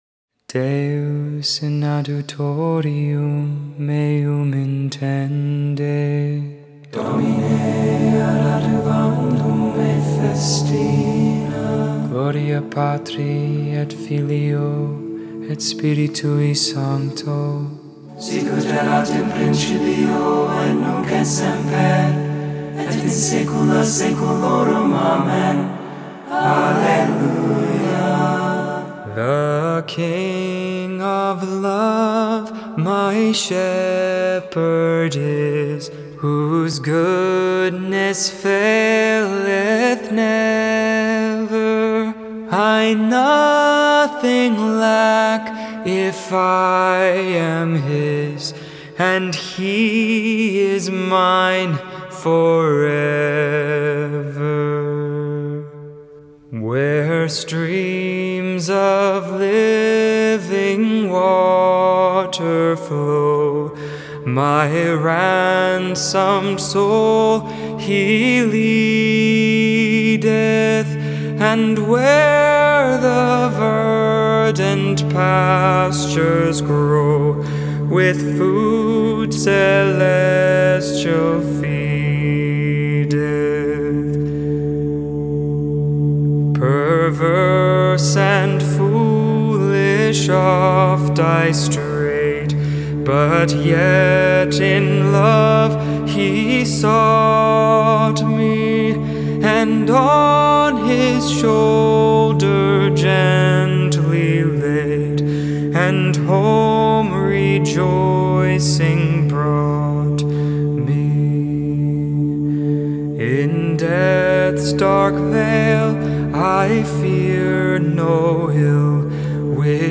Lauds for the Third Tuesday in Ordinary Time.